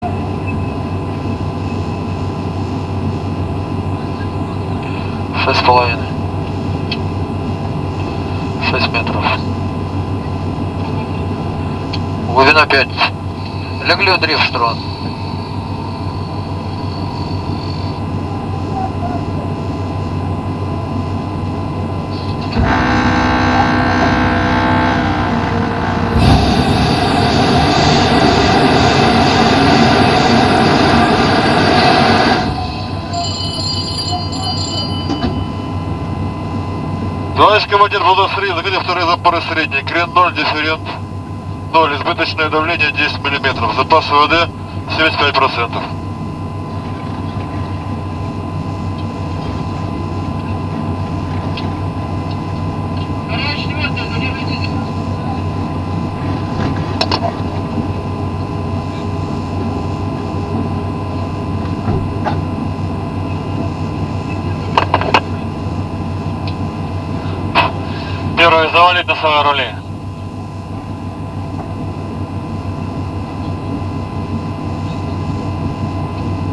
Шум всплывающей подводной лодки